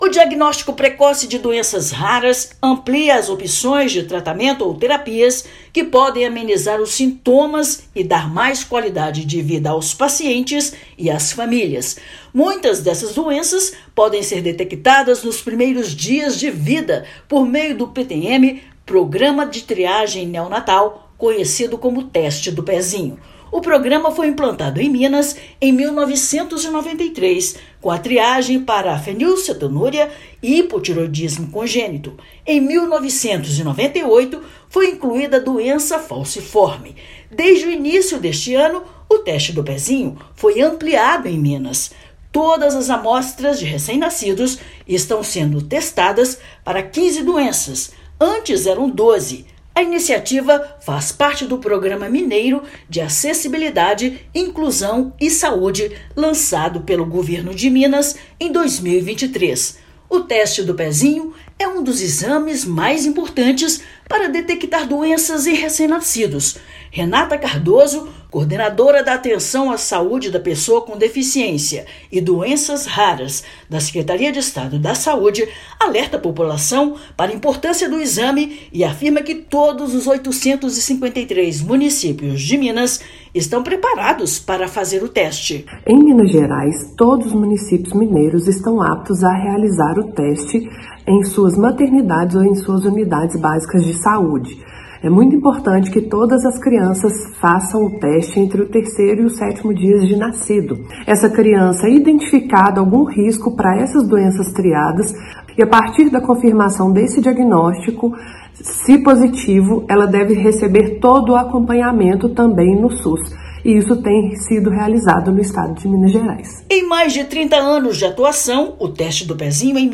[RÁDIO] Teste do Pezinho completa 31 anos em Minas Gerais
Exame foi ampliado no estado em janeiro de 2024, passando de 12 para 15 as doenças triadas. Ouça matéria de rádio.